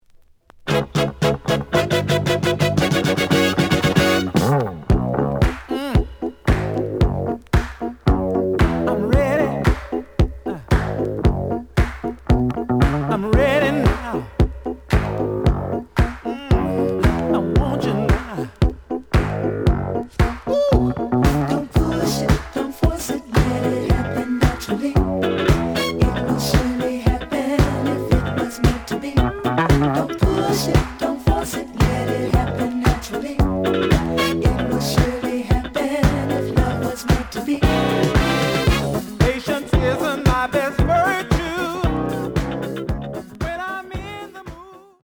The audio sample is recorded from the actual item.
●Genre: Disco
Slight damage on A side label. Plays good.)